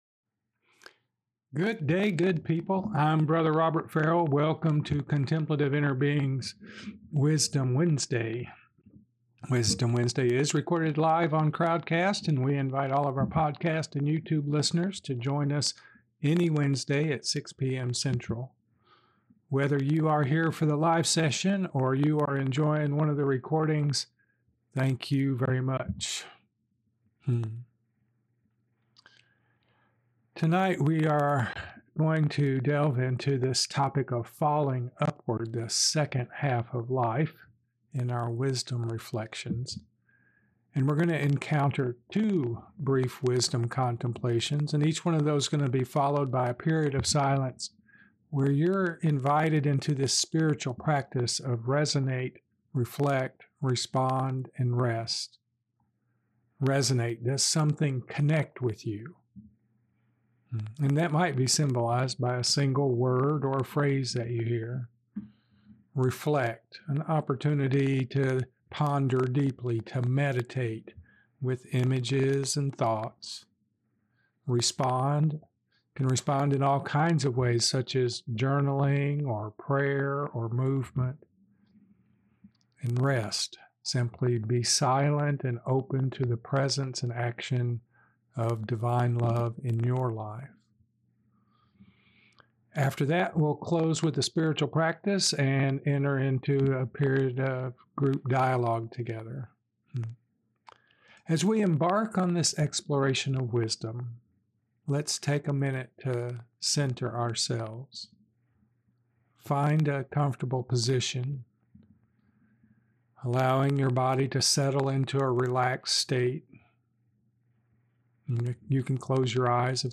Wisdom Wednesday is recorded live on Crowdcast.